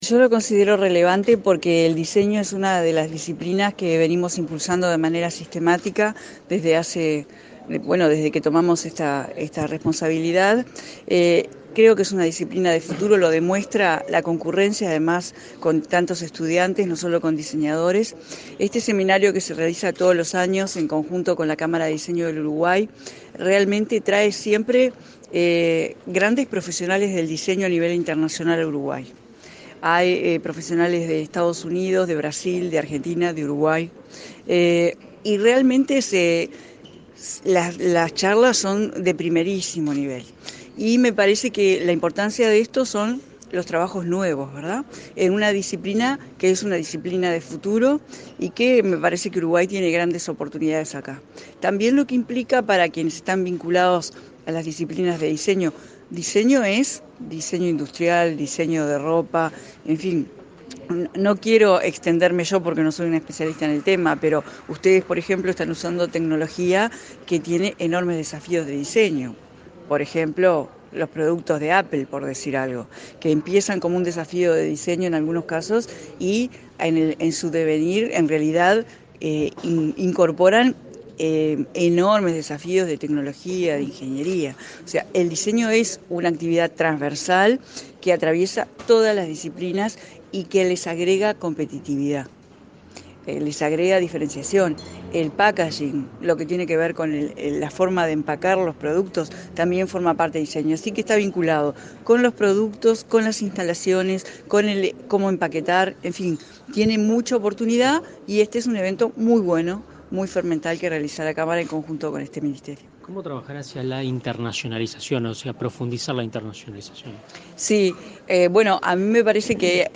La ministra de Industria, Carolina Cosse, participó este viernes del 6.º Evento Anual de Diseño DUY.